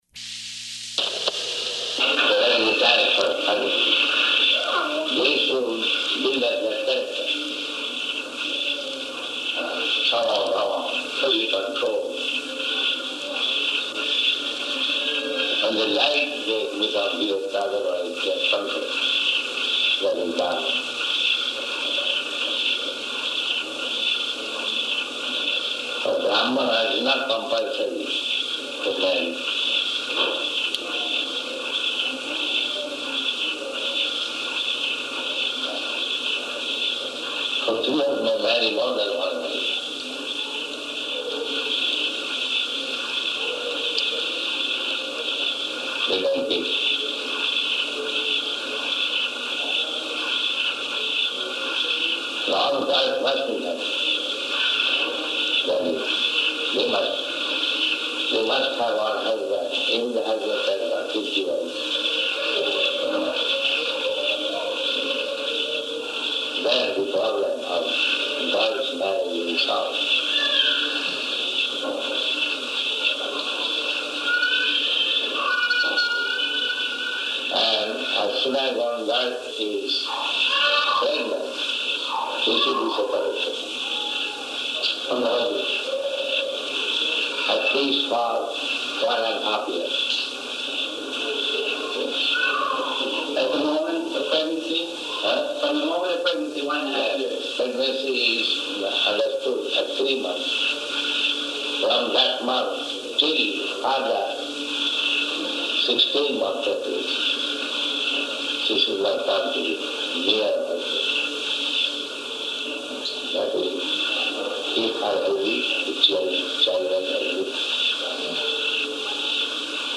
Room Conversation with Devotees